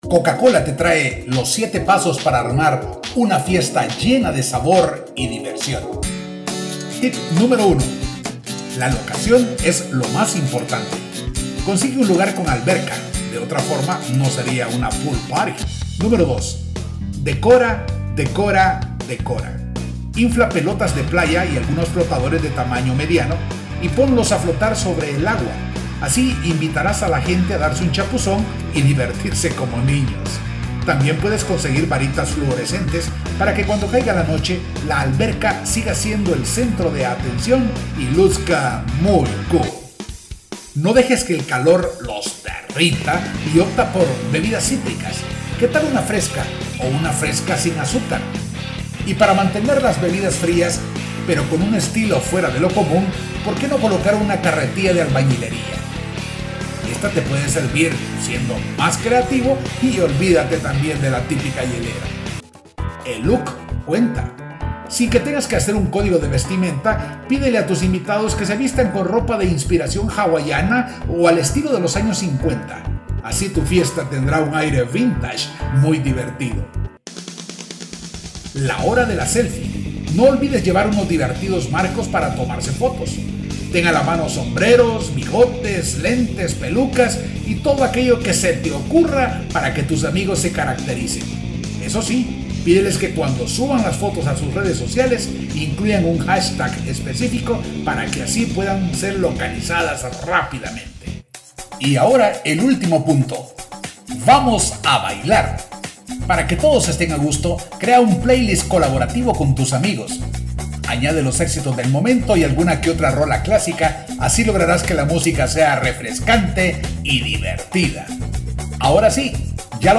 Spanish native speaking voice over, with a Neural Tone.
Sprechprobe: Industrie (Muttersprache):